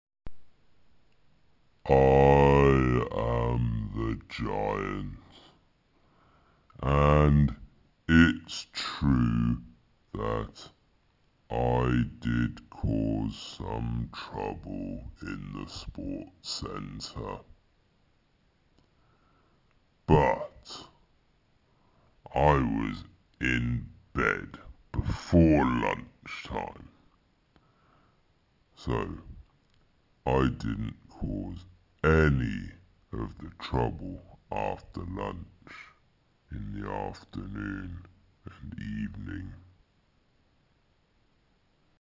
Listen to the giant
sport-centre-giant.mp3